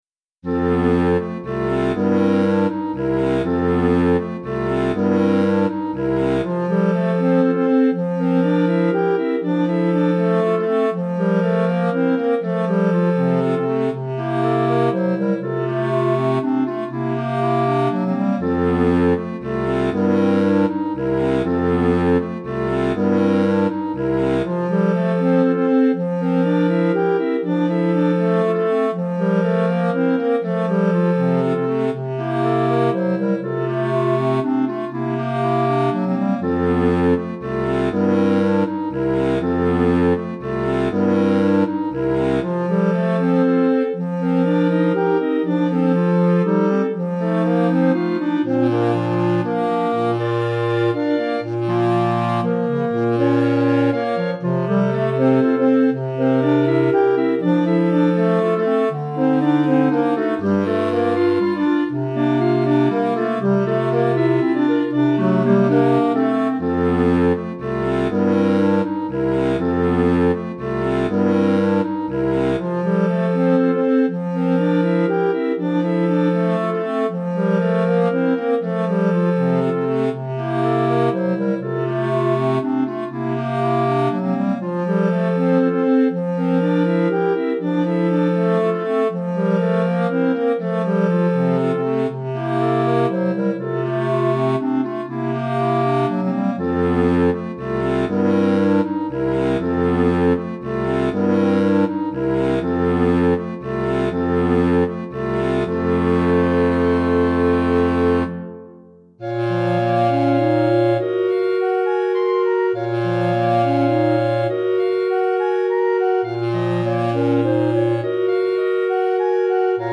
5 Clarinettes